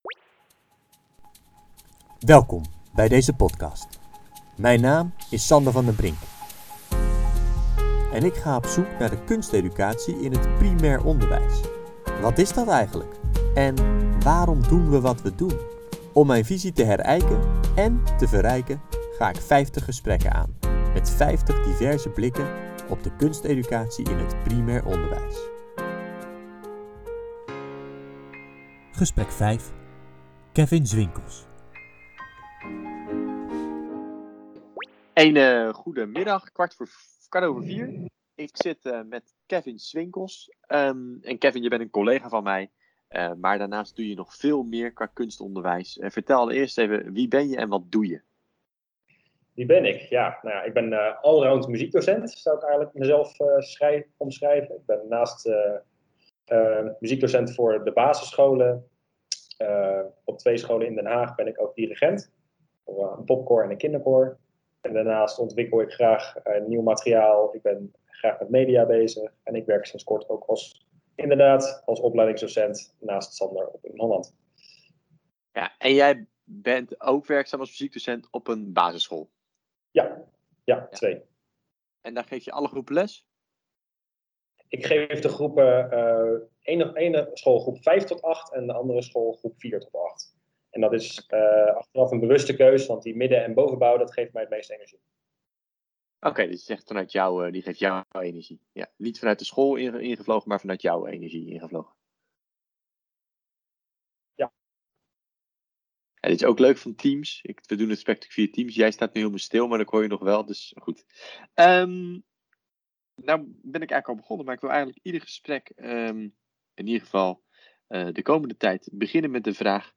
Om mijn visie op kunsteducatie te verdiepen en nog meer zicht te krijgen op de kunsteducatie in het primair onderwijs ga ik 50 gesprekken aan met 50 diverse betrokkenen in dit werkveld. Kunsteducatie in het primair onderwijs, waarom doen we het?